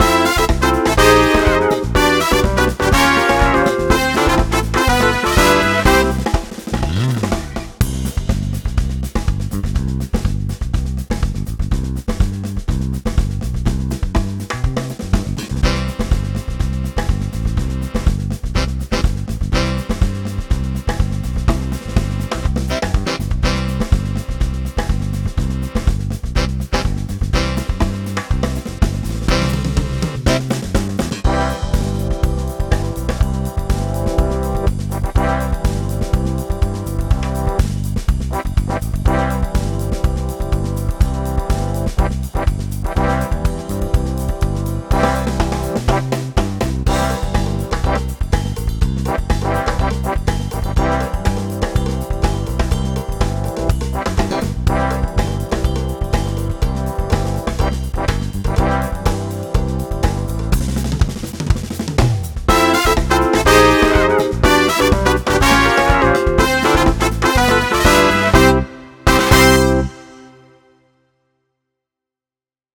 SAMBA POP.mp3